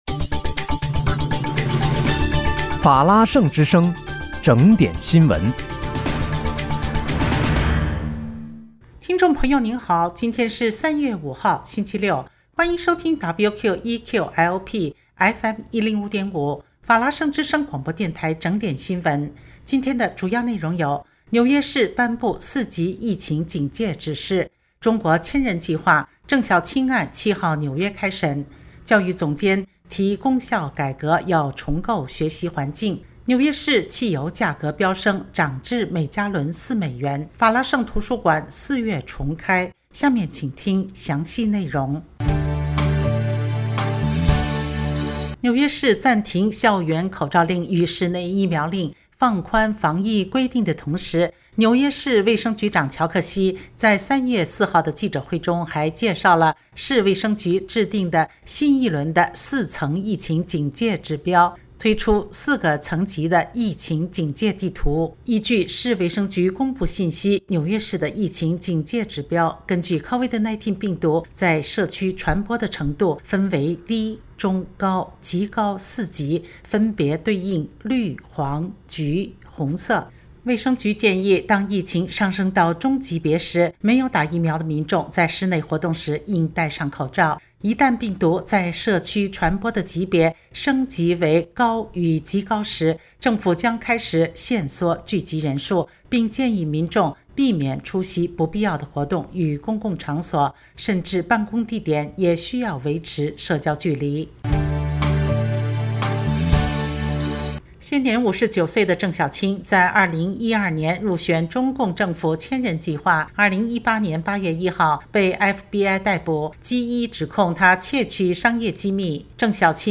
3月5日（星期六）纽约整点新闻
听众朋友您好！今天是3月5号，星期六，欢迎收听WQEQ-LP FM105.5法拉盛之声广播电台整点新闻。